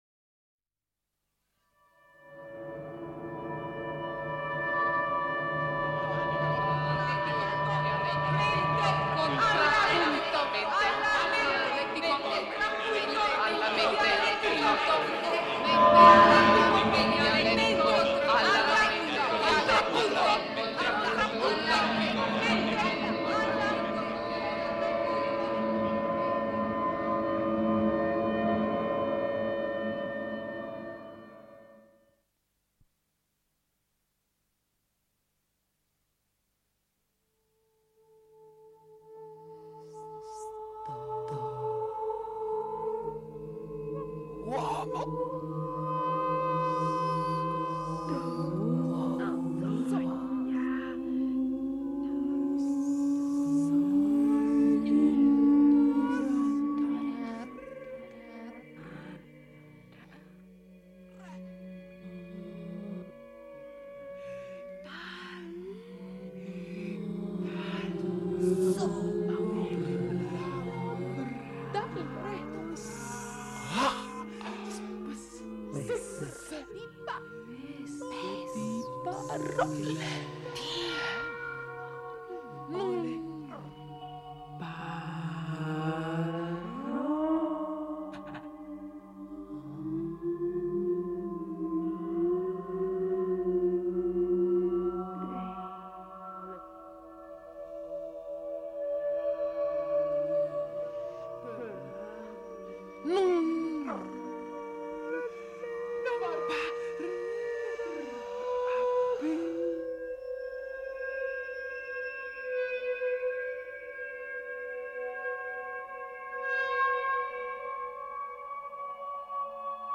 Computer music